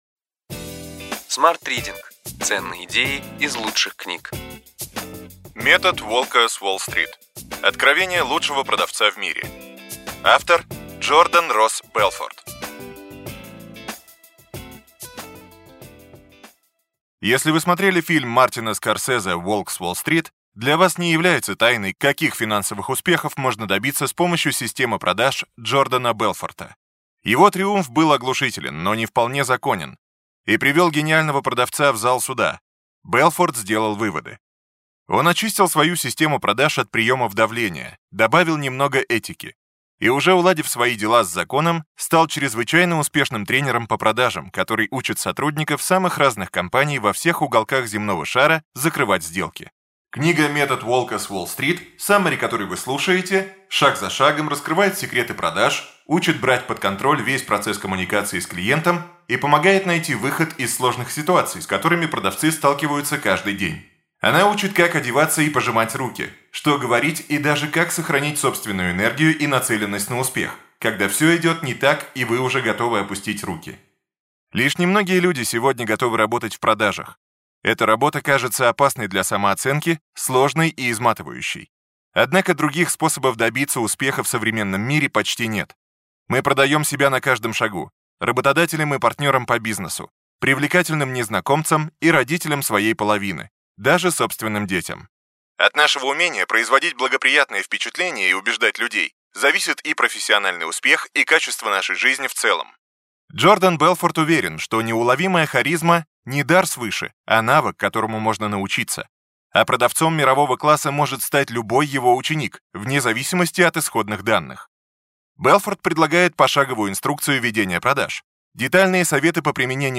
Аудиокнига Ключевые идеи книги: Метод «Волка с Уолл-стрит»: откровения лучшего продавца в мире.